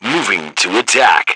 H_soldier2_21.wav